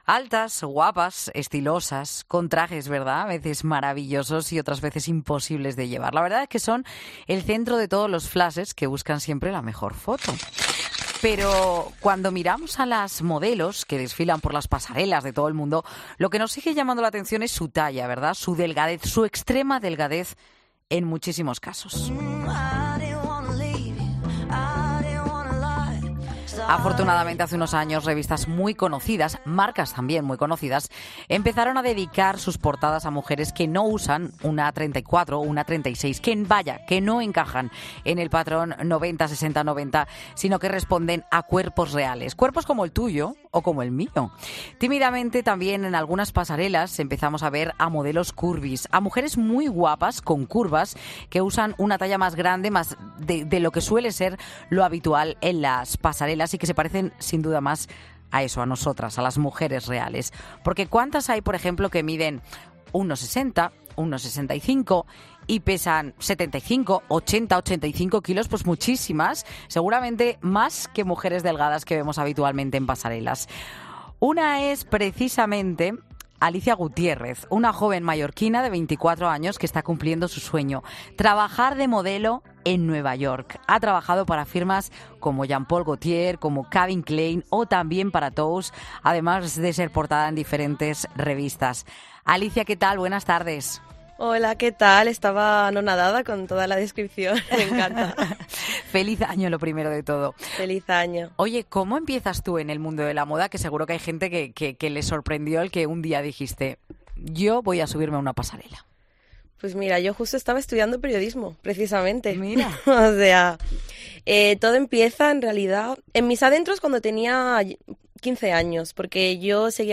No te pierdas el resto de la entrevista en el audio adjuntado a esta noticia.